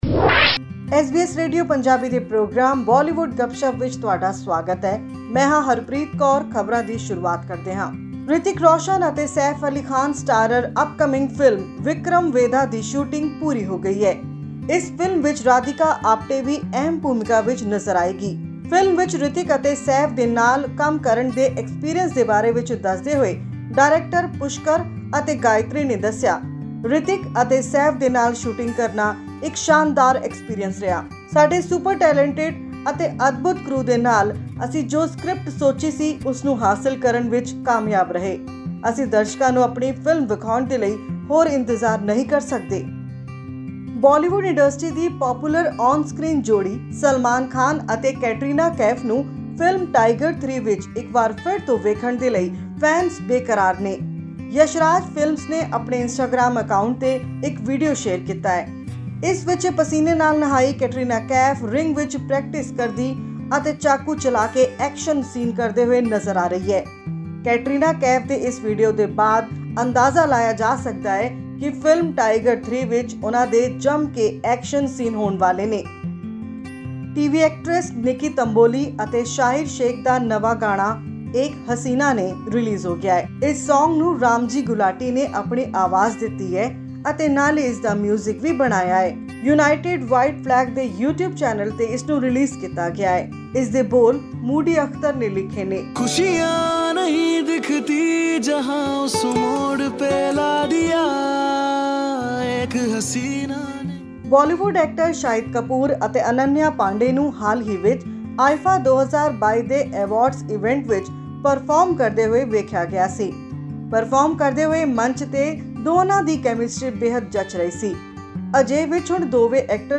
She is paired with Punjabi singer and actor Jassie Gill in this multi-starrer film. Listen to this audio report for more details...